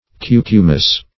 Search Result for " cucumis" : Wordnet 3.0 NOUN (1) 1. cucumbers ; muskmelons ; [syn: Cucumis , genus Cucumis ] The Collaborative International Dictionary of English v.0.48: Cucumis \Cu"cu*mis\ (k?k?--m?s), n. [L., cucumber.]